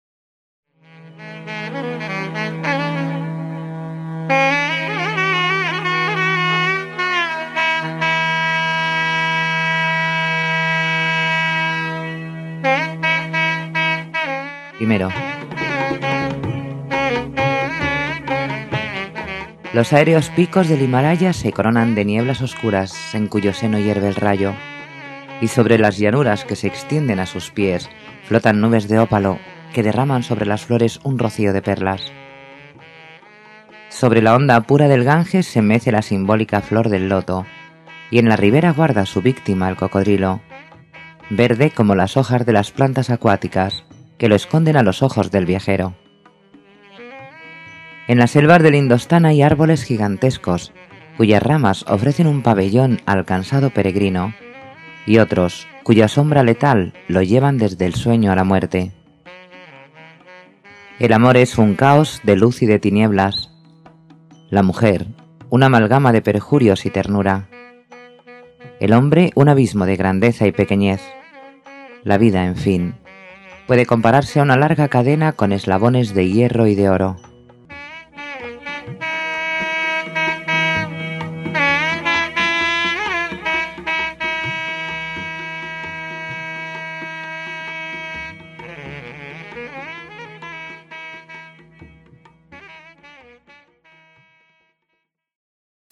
Leyenda
Música: Tom Fahy (cc:by-sa)